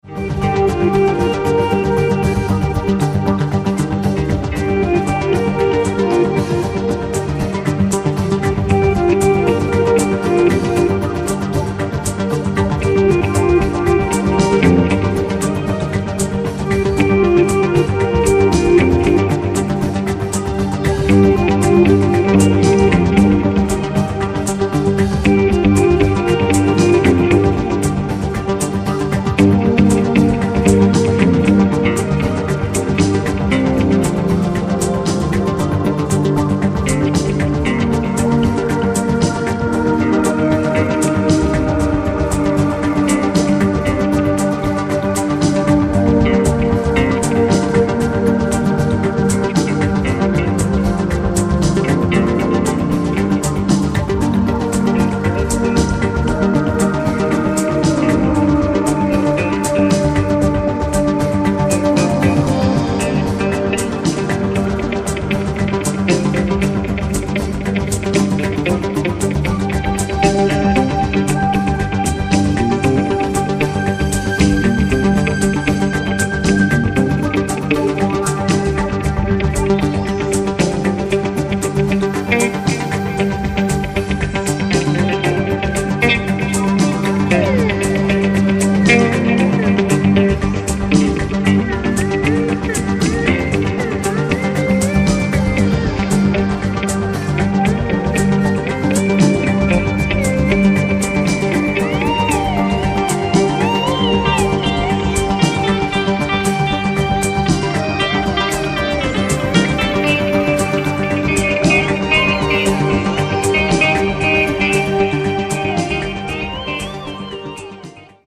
20.02.2005 w klubie DELTA